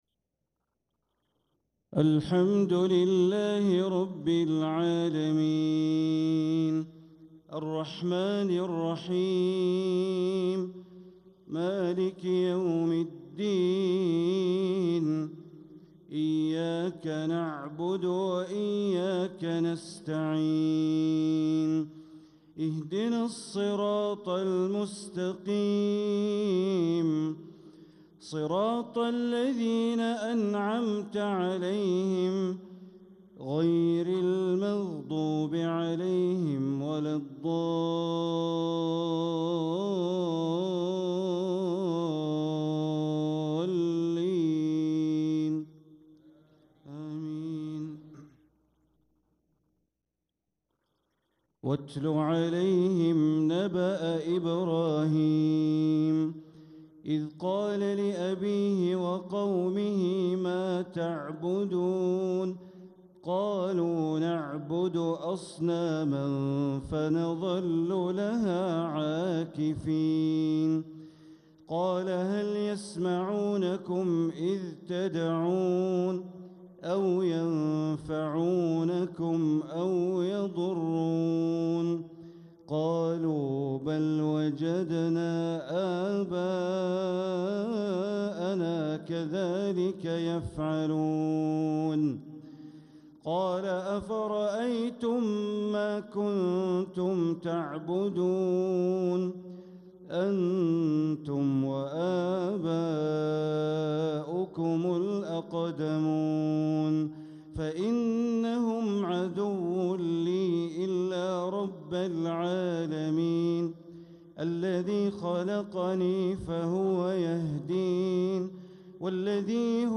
تلاوة من سورة الشعراء ٦٩-١٠٤ | عشاء الجمعة ٤ ربيع الآخر ١٤٤٧ > 1447هـ > الفروض - تلاوات بندر بليلة